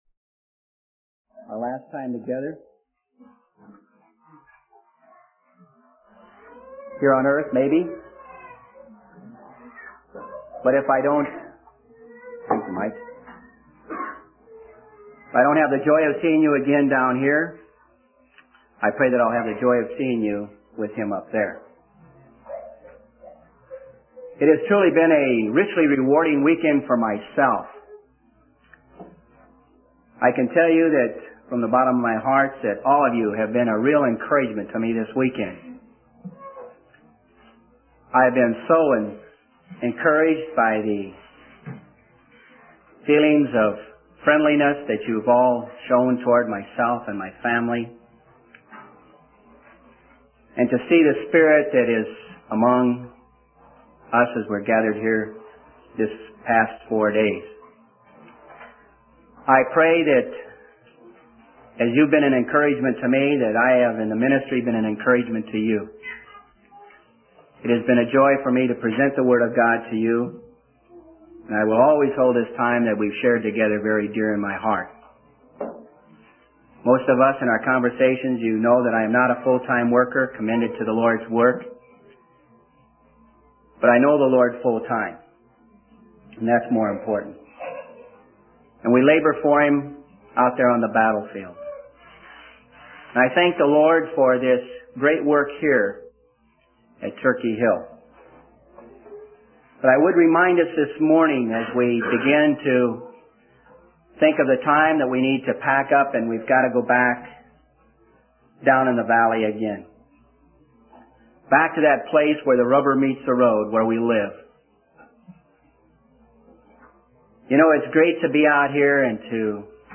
In this sermon, the preacher recounts the story of a mother who approached Jesus in desperation to heal her demon-possessed daughter.